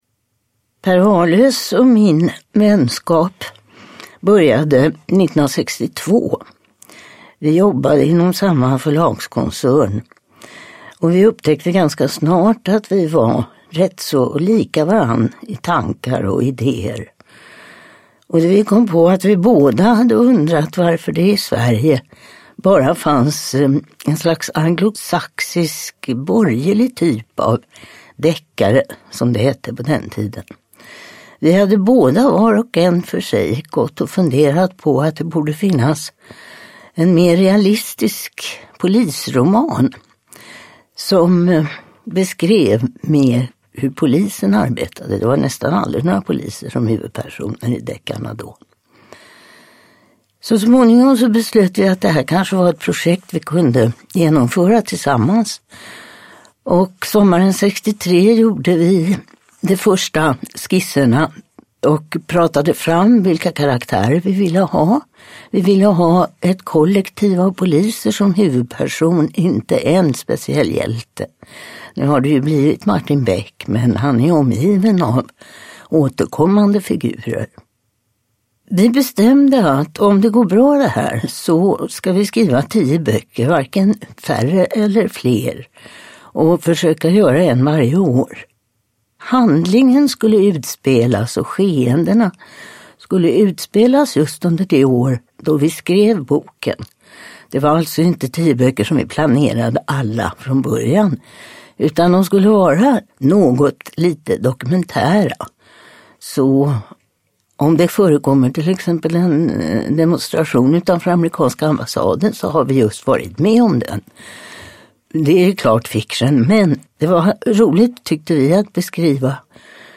Mannen på balkongen – Ljudbok
Uppläsare: Torsten Wahlund